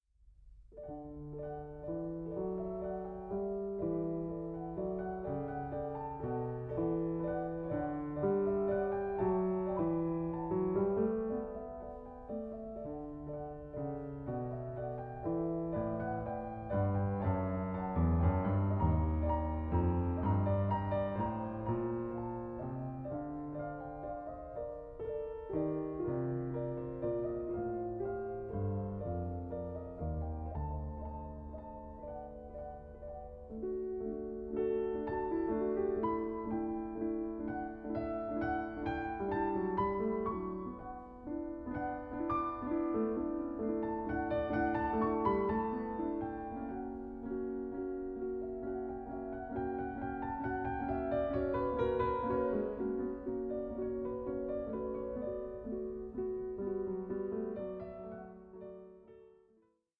8) No. 4, in E Minor: Fugue 4:47